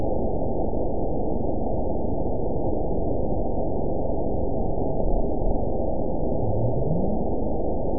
event 912144 date 03/19/22 time 08:49:50 GMT (3 years, 1 month ago) score 9.65 location TSS-AB05 detected by nrw target species NRW annotations +NRW Spectrogram: Frequency (kHz) vs. Time (s) audio not available .wav